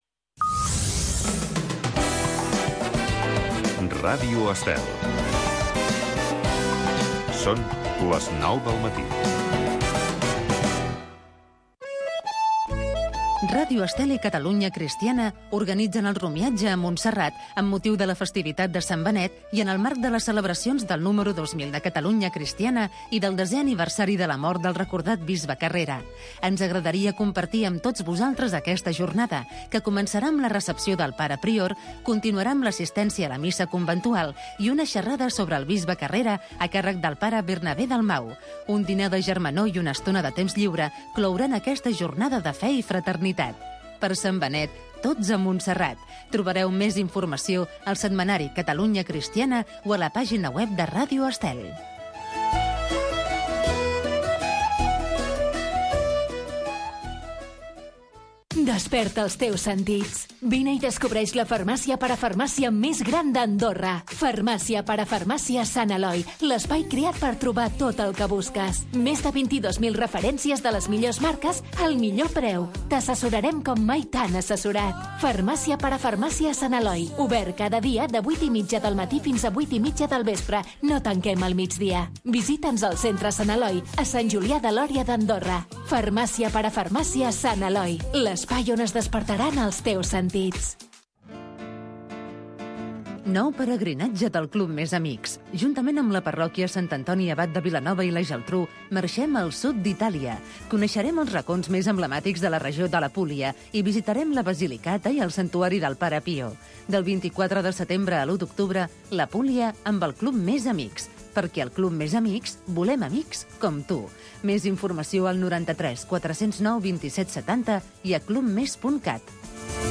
Indicatiu de l'emissora, romiatge al monestir de Montserrat de Catalunya Cristiana, publicitat, promoció de "Tot sardana", indicatiu, careta del programa, presentació dels grups d'havaneres que es podran escoltar al programa
Musical
FM